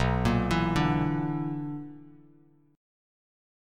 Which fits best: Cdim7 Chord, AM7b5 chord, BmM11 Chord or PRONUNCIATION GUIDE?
BmM11 Chord